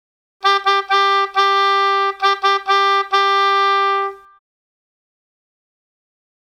groep6_les1-3-5_hoboritme4.mp3